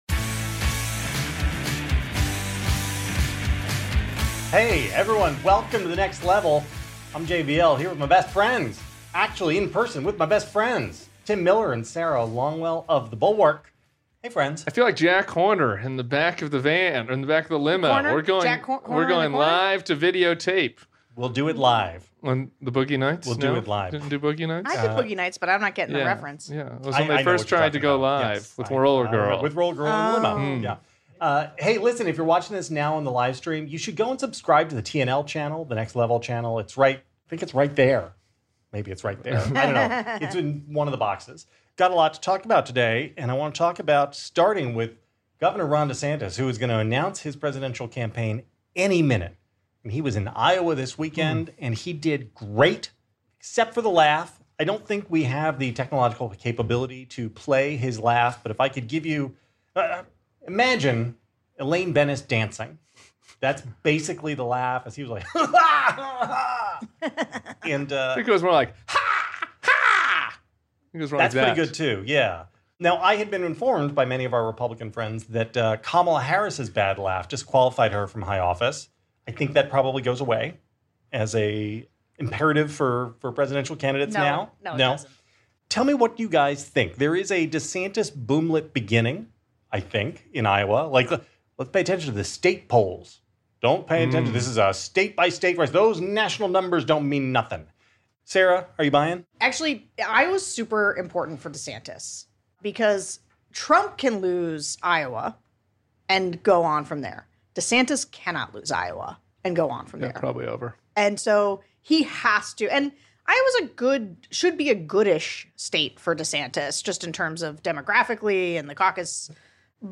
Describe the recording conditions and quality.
Plus, the gang takes questions from the audience!